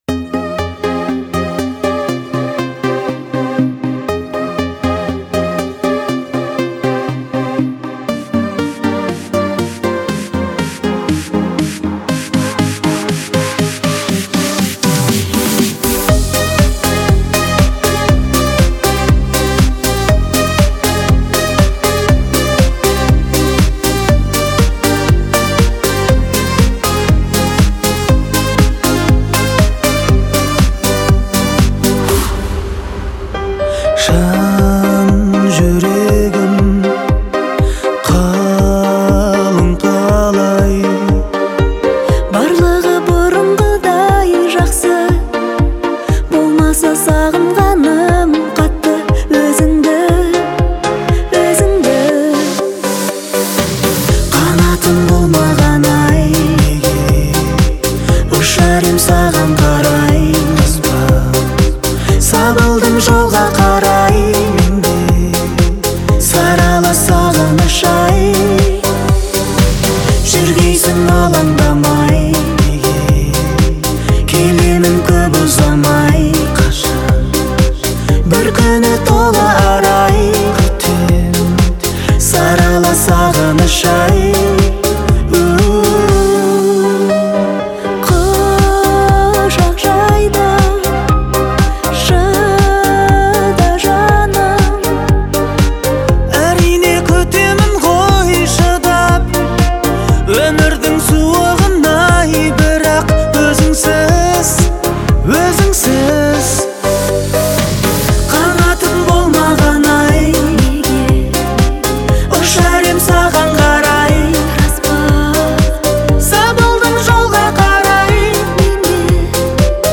это романтичная песня в жанре поп